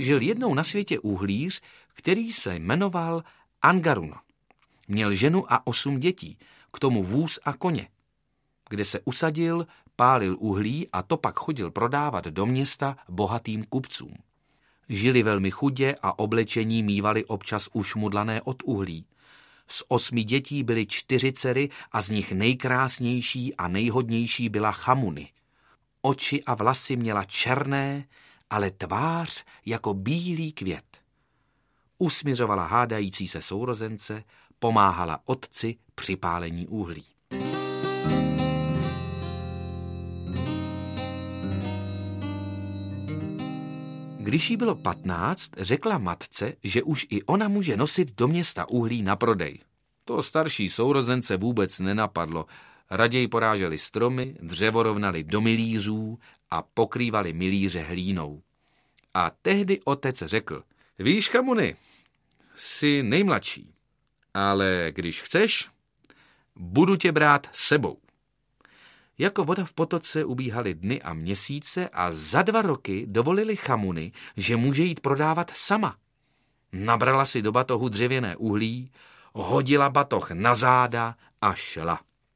Svět pohádek audiokniha
Jeden z nejpopulárnějších českých herců současnosti, Petr Rychlý, se stal v nahrávacím studiu vypravěčem pohádek z různých koutů světa, které mohou děti nejen pobavit, ale také seznámit s hrdiny a postavami pohádek z Anglie, Norska, Japonska, Irska, Islandu či Katalánska. V celkem devíti pohádkových příbězích oživil Petr Rychlý svým hlasem více než dvě desítky figurek, postav a postaviček a dokázal, že je skvělým vypravěčem a hercem mnoha hlasů a výrazů.
• InterpretPetr Rychlý